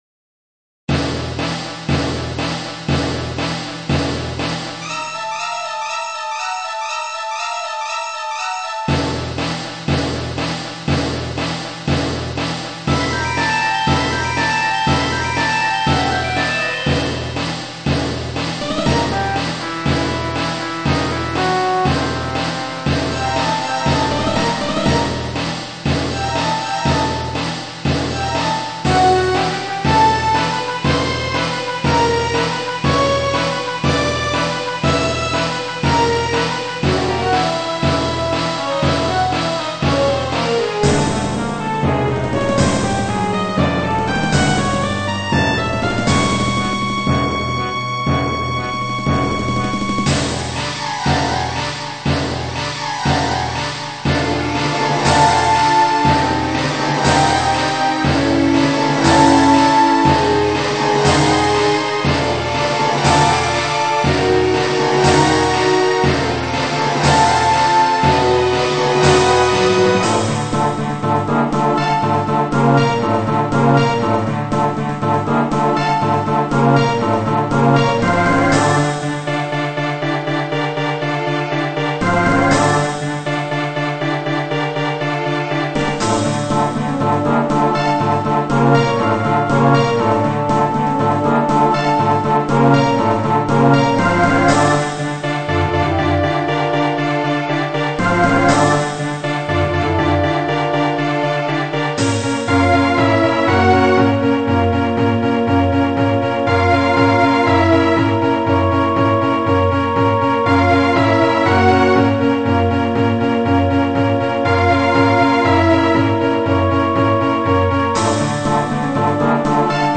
初めて聞いたときはビックリしますよね、声が入ってますから。